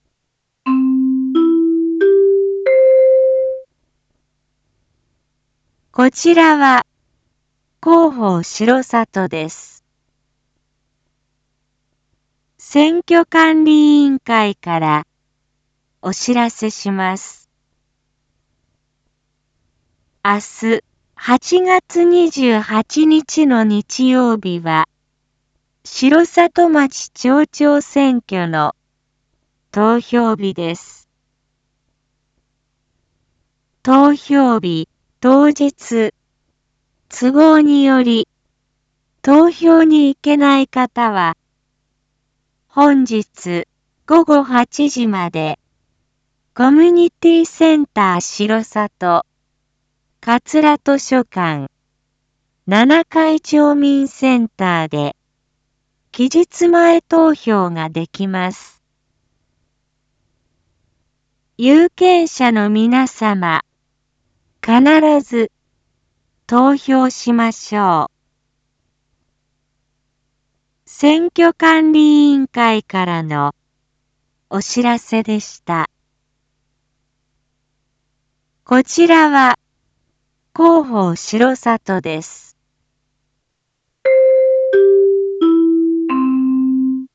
一般放送情報
Back Home 一般放送情報 音声放送 再生 一般放送情報 登録日時：2022-08-27 19:01:28 タイトル：城里町町長選挙③前日 インフォメーション：こちらは広報しろさとです。